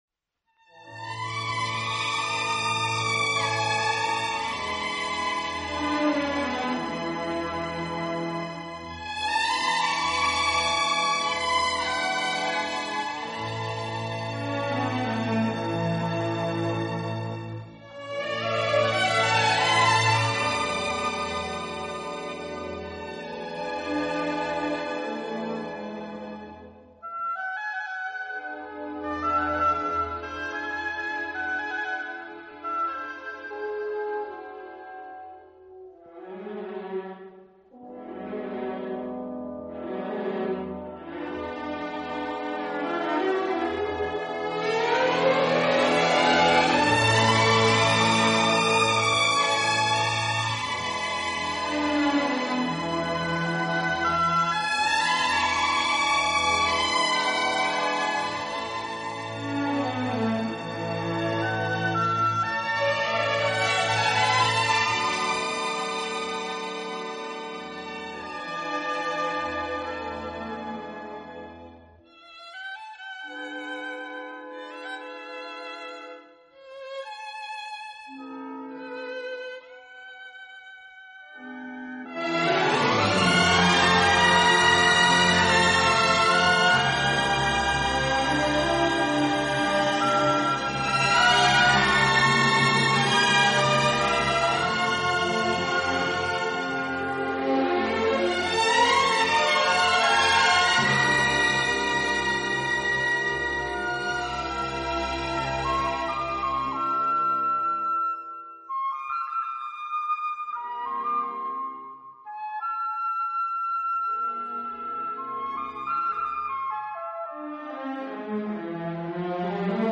Genre: Instrumental
舒展，旋律优美、动听，音响华丽丰满。